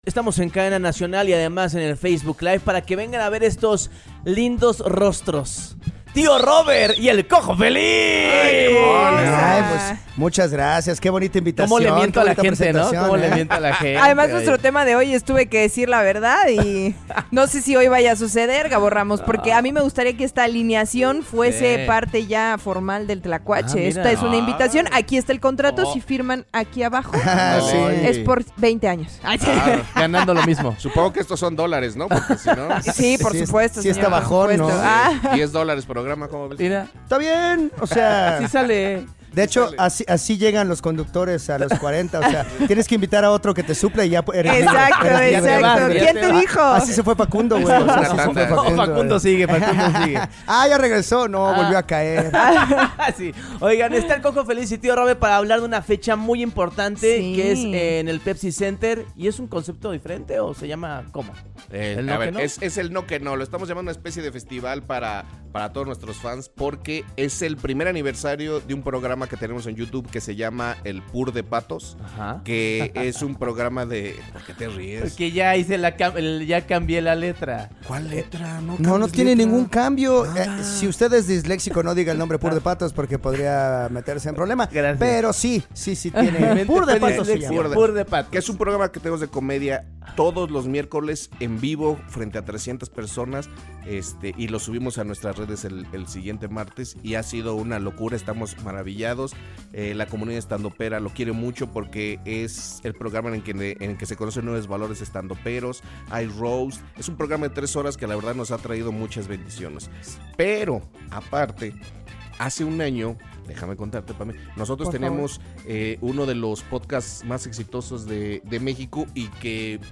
Entrevistamos a este dúo de comediantes increíbles que aparte de traer show nuevo, nos dieron una exclusiva acerca de su podcast.